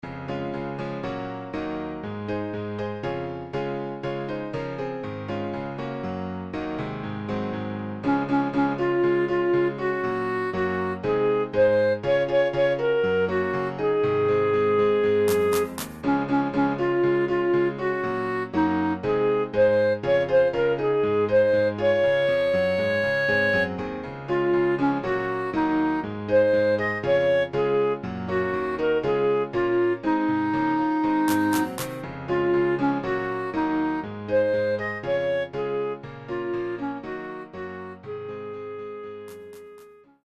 Sample from the Rehearsal CD